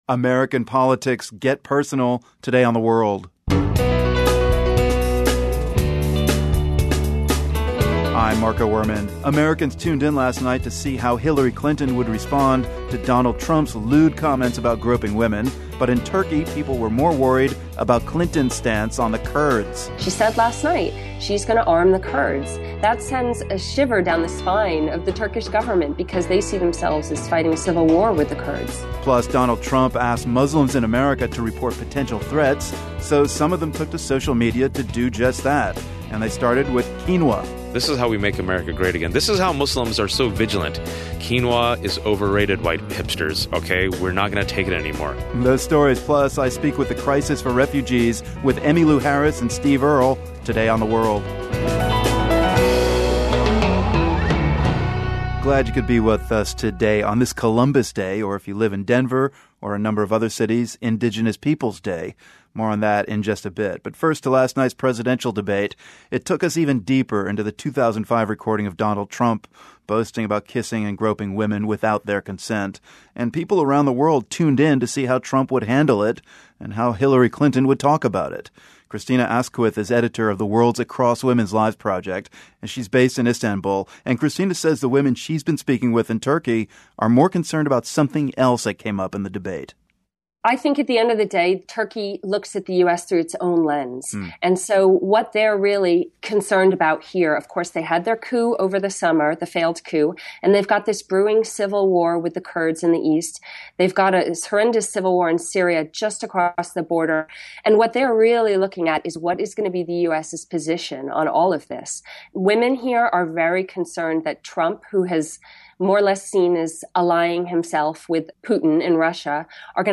We'll get a sense of how women around the world are reacting to the Trump video and the debate. Plus, we hear how climate change is playing among millennials as a campaign issue. And singer-songwriter greats Emmylou Harris and Steve Earle talk about their involvement in a tour to draw attention to the refugee crisis.